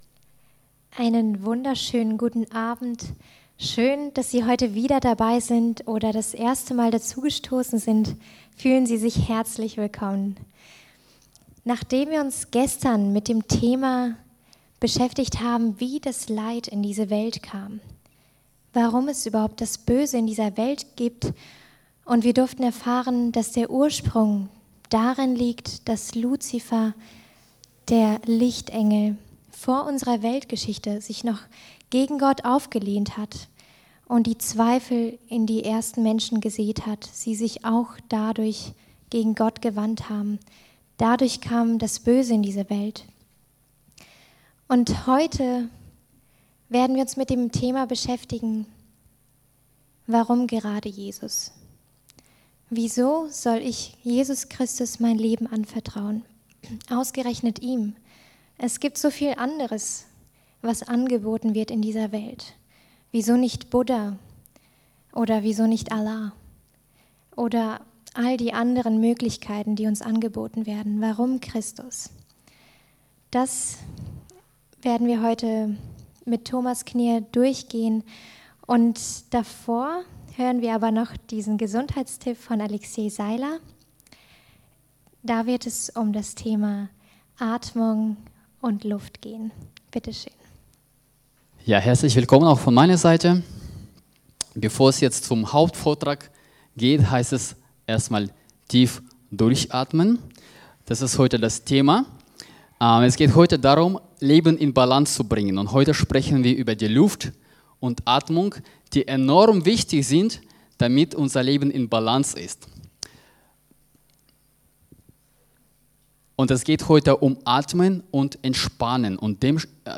Predigten in mp3 - Blog